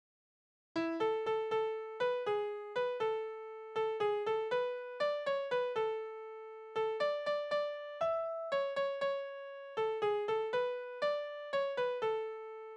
Balladen: Er findet seine Liebste sterbend wieder
Tonart: A-Dur
Taktart: 2/4
Tonumfang: Oktave
Besetzung: vokal